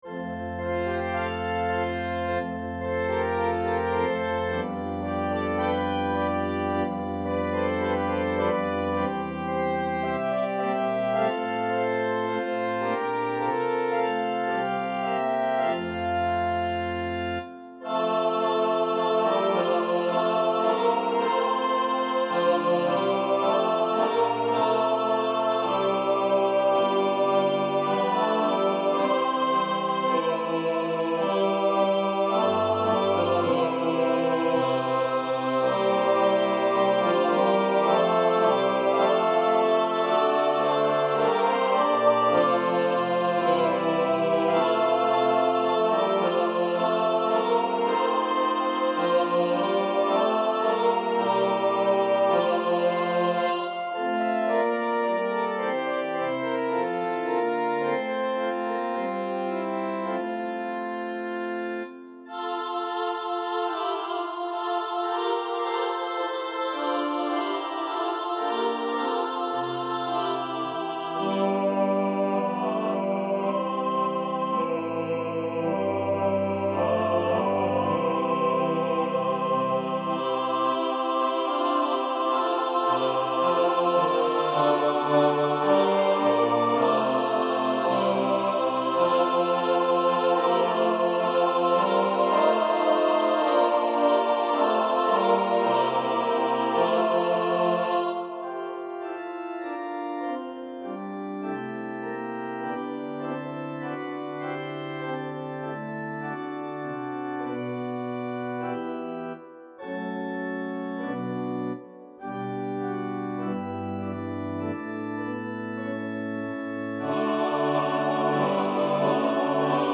Festive hymn-anthem